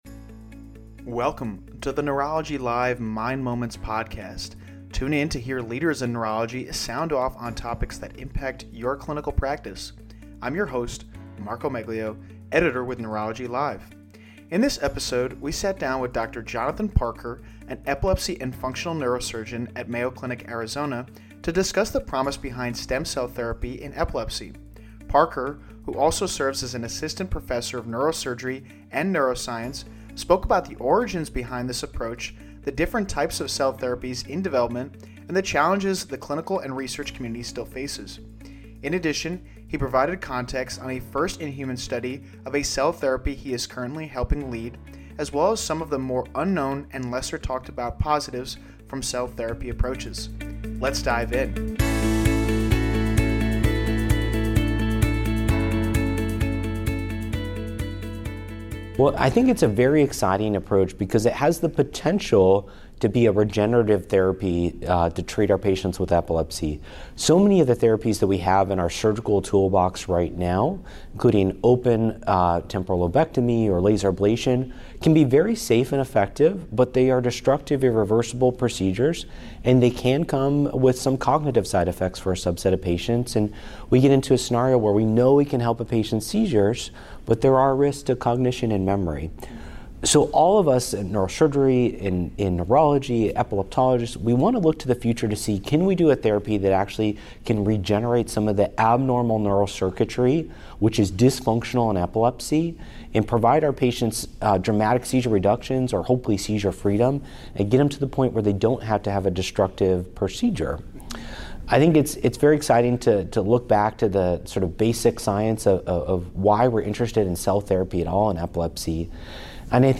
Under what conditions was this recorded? The discussion, which took place at the 2024 American Epilepsy Society (AES) Annual Meeting in Los Angeles, California, covers the thought process behind this approach and why it may hold greater advantages over other traditional surgeries that result in detrimental cognitive effects.